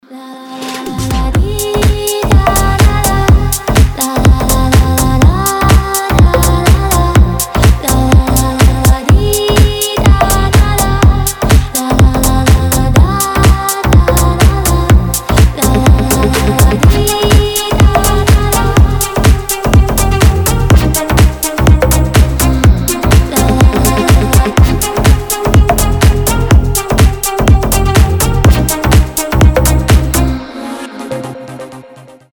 • Качество: 320, Stereo
громкие
deep house
Club House
future house
басы